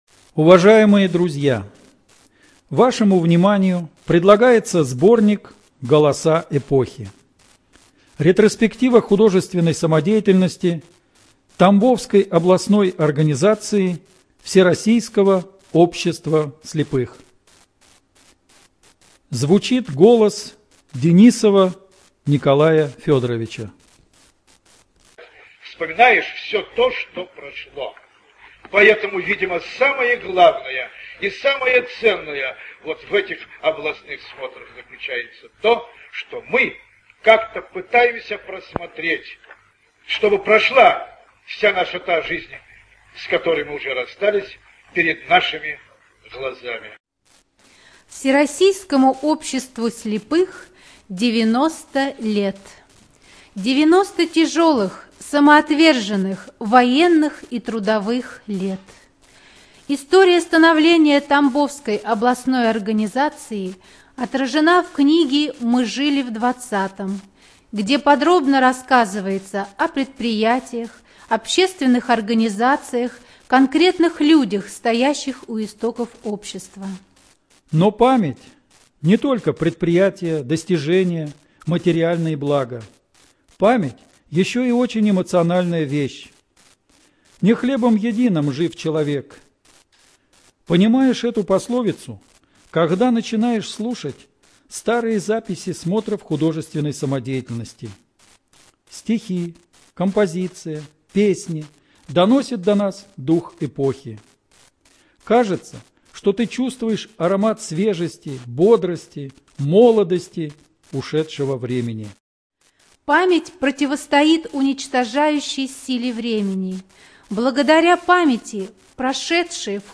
Ретроспектива художественной самодеятельности Тамбовской РО ВОС